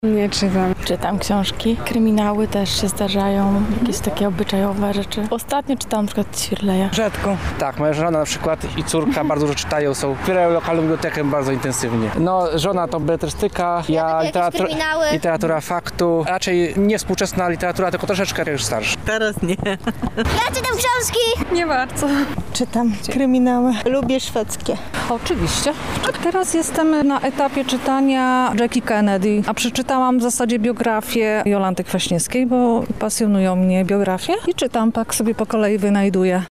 Sonda: książki
sondaksiazki_01.mp3